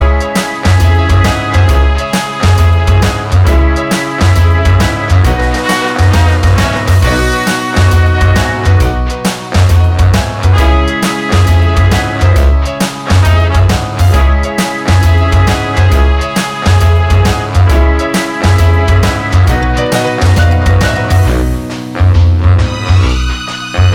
No Guitars Pop (2000s) 3:07 Buy £1.50